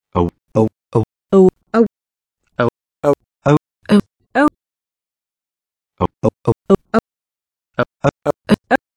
As with /tʃ/, there’s the option of choosing alternative symbols, and this is supported by the fact that the starting qualities of away and oasis are different, contrary to both the traditional and the CUBE systems: away begins with a more back quality than oasis. In the following clip you can hear 1. the initial /əw/ from five dictionary recordings of away; 2. the initial GOAT vowel from five recordings of oasis; 3. the first 50 milliseconds of the away recordings; 4. the first 50 ms of the oasis recordings:
away-oasis-onsets.mp3